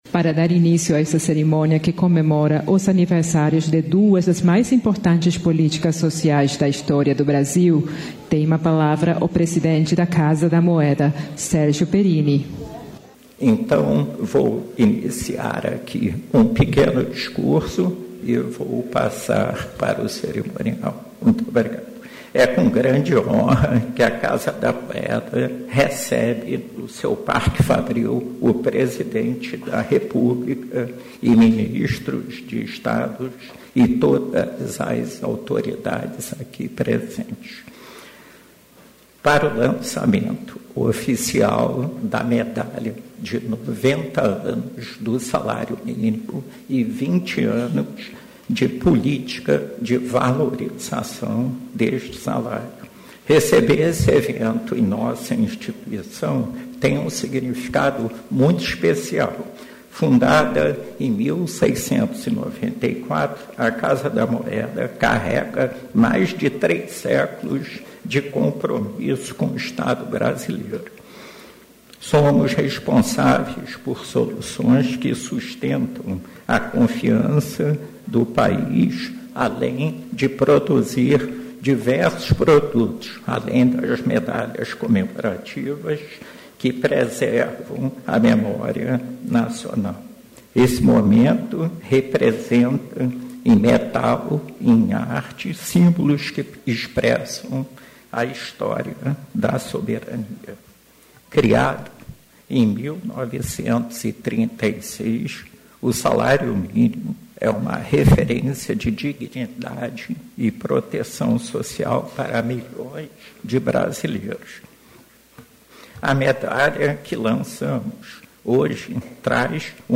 Íntegra da coletiva de imprensa com o ministro da Justiça e Segurança Pública, Ricardo Lewandowski, do diretor-geral da Polícia Federal, Andrei Rodrigues, e do secretário-geral da Interpol, Valdecy Urquiza, nesta segunda-feira (19), em Brasília.